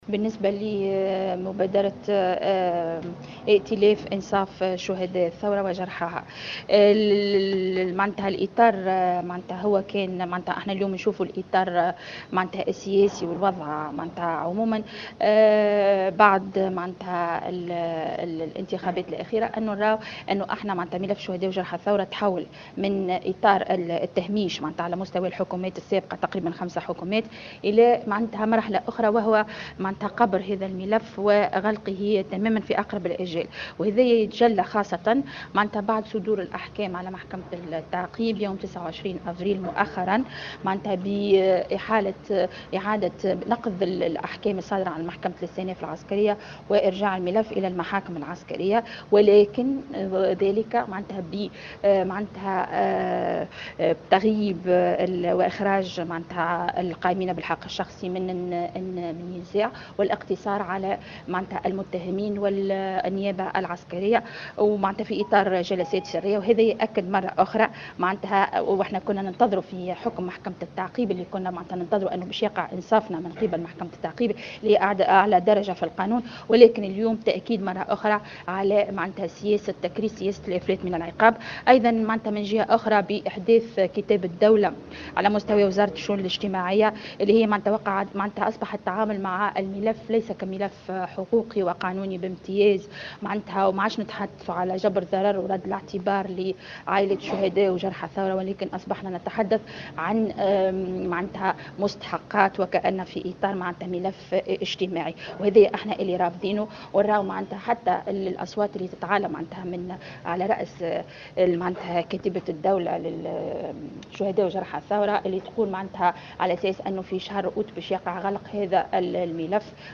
خلال ندوة صحفية انتظمت اليوم بالعاصمة